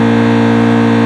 Engines